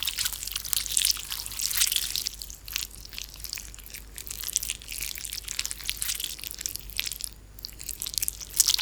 Slime-Moving.ogg